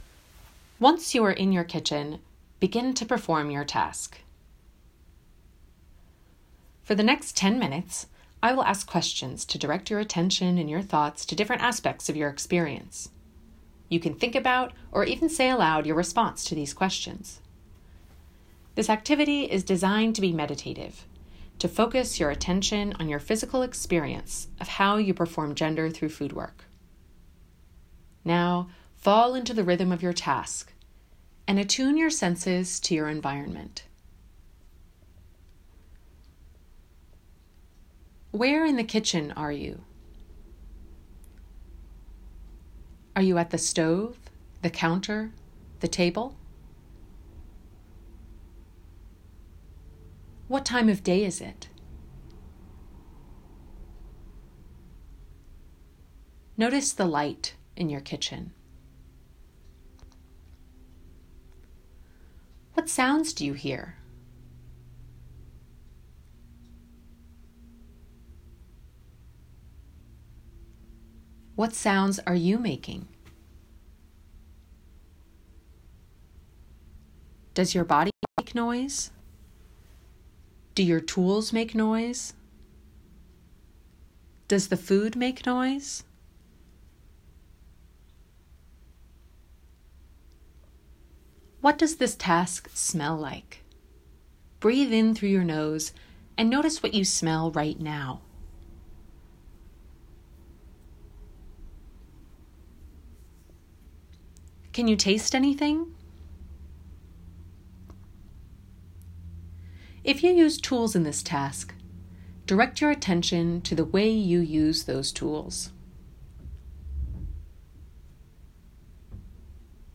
As you perform the task, the recording will guide you in a reflection on your physical experience, with opportunities to sense for how gender is embodied in your actions.